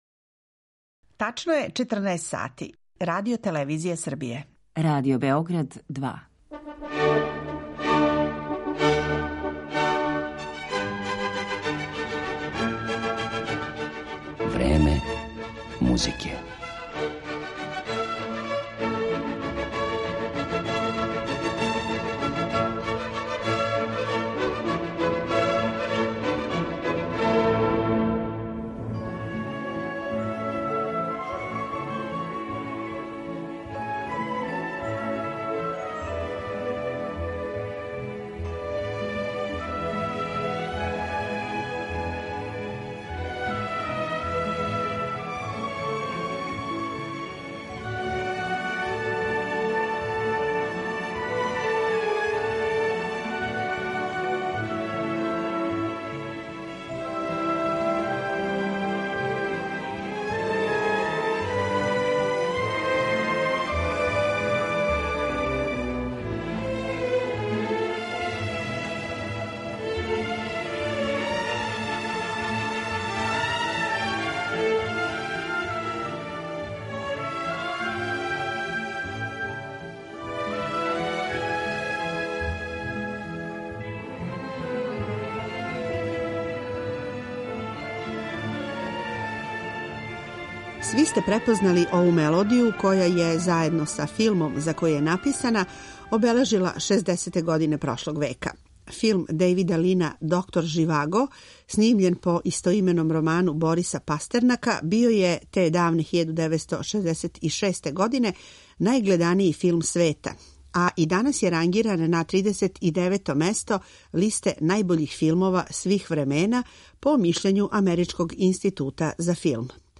muziku za filmove